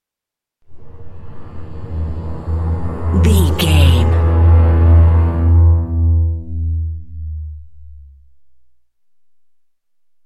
Vehicle sci fi pass by car ship
Sound Effects
strange
high tech